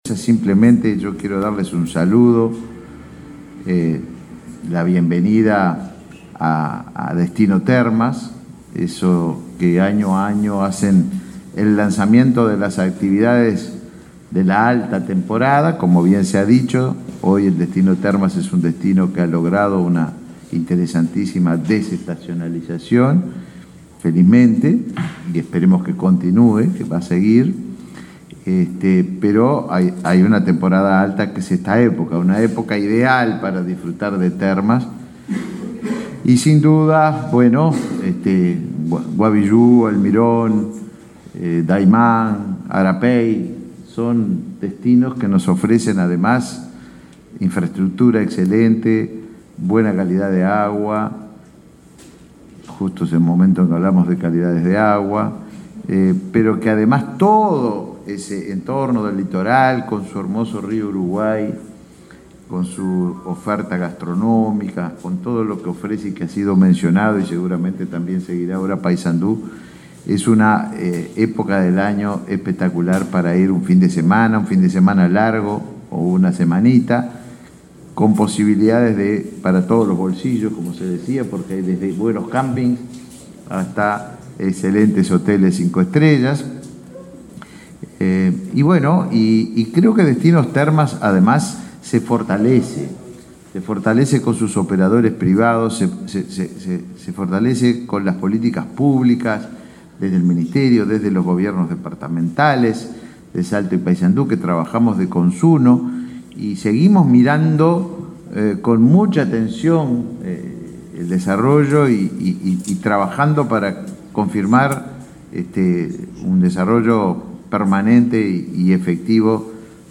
Palabras de autoridades del Ministerio de Turismo